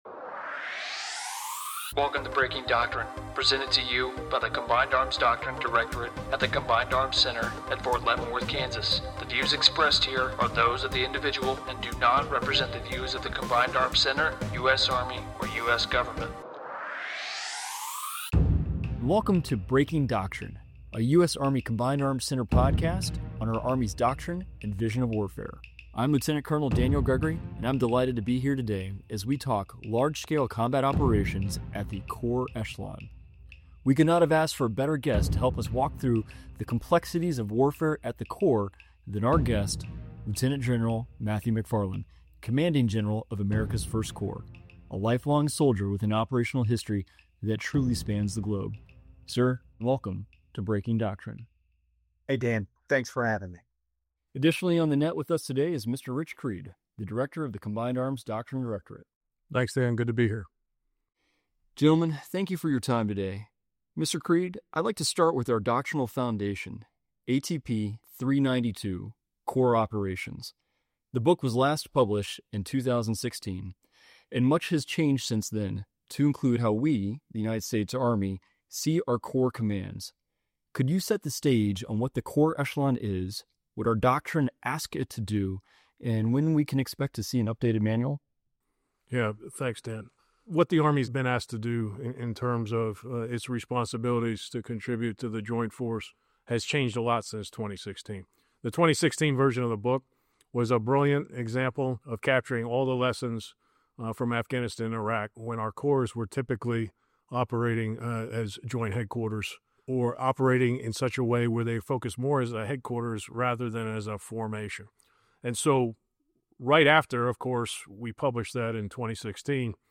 The podcast will explore timely topics of interest, regarding current and emerging Army and joint doctrine. Our guests will include a broad range of Army and other Service professionals with the vast knowledge and years of experience required to facilitate discussion on a variety of military doctrine-related topics.